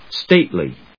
/stéɪtli(米国英語), ˈsteɪtli(英国英語)/